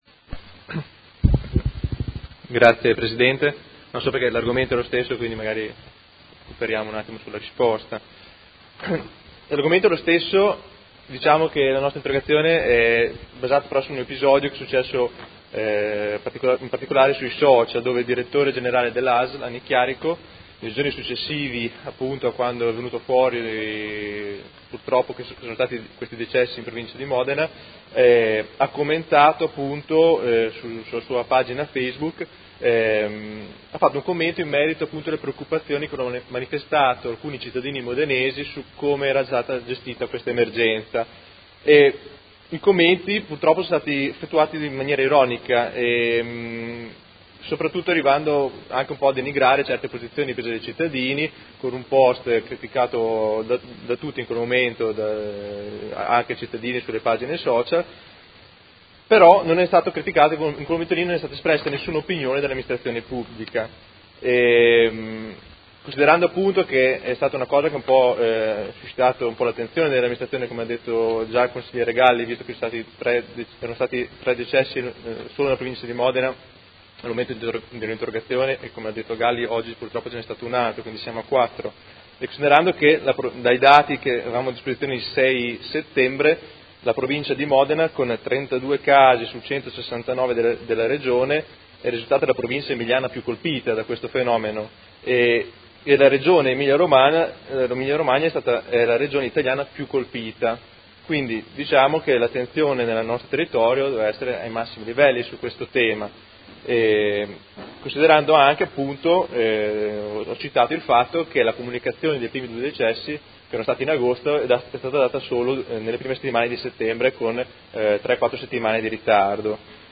Seduta del 04/10/2018 Interrogazione del Gruppo Consiliare Movimento cinque Stelle avente per oggetto: Virus West Nile